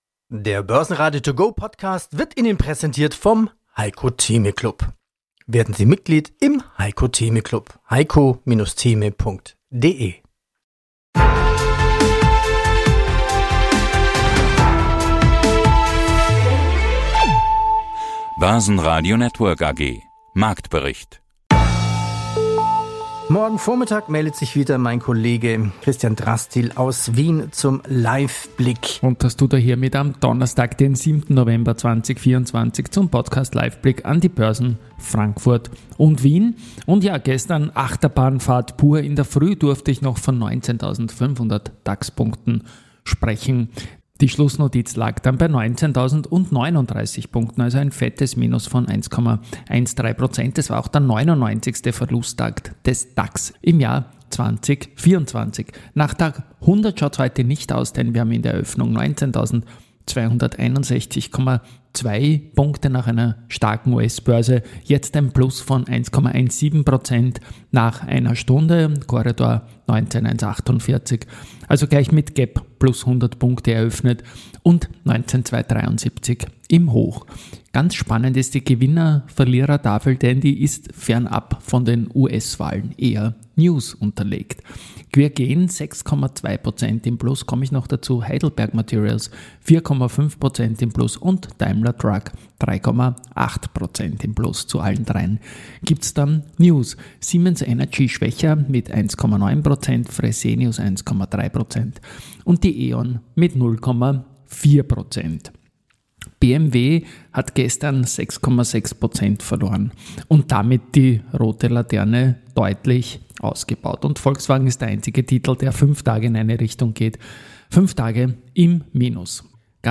Die Börse zum hören: mit Vorstandsinterviews, Expertenmeinungen und Marktberichten.